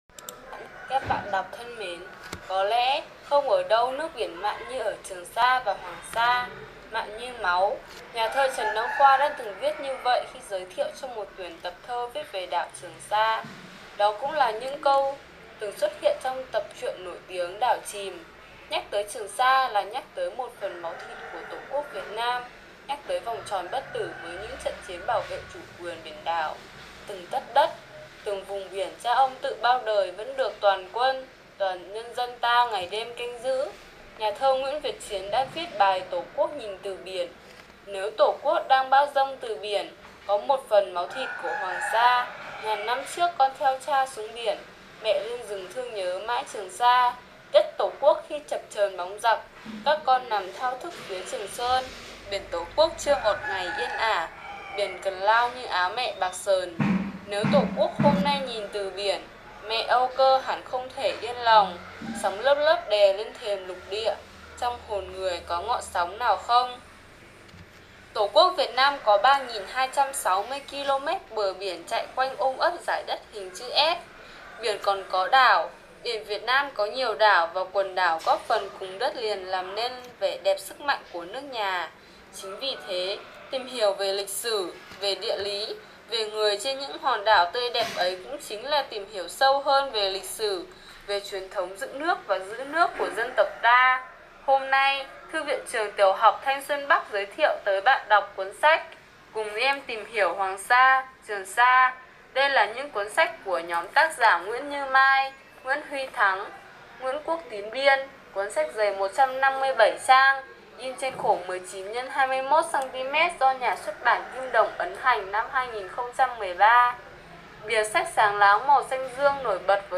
Sách nói | Cùng em tìm hiểu Hoàng Sa - Trường Sa